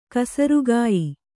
♪ kasarugāyi